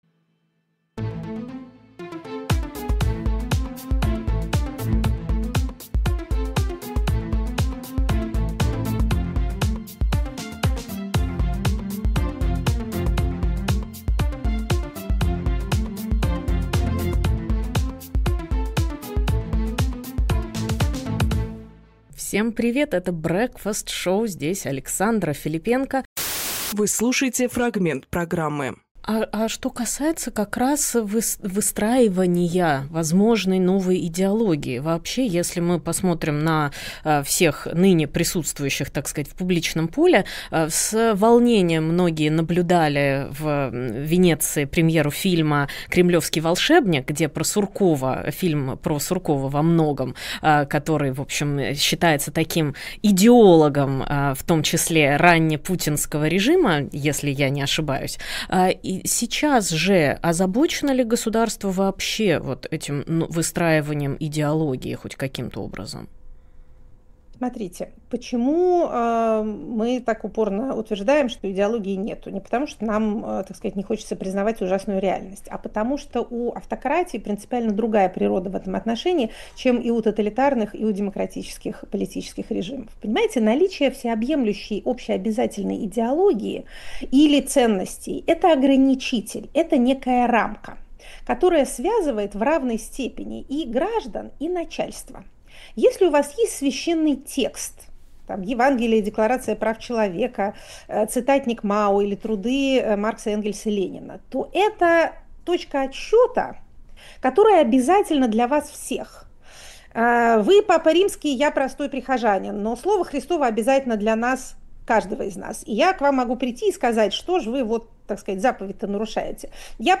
Фрагмент эфира от 01.09.25